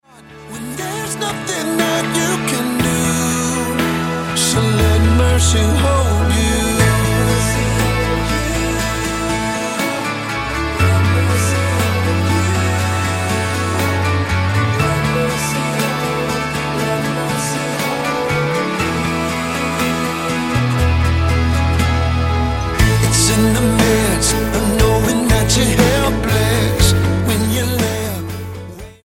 Southern gospel and pop man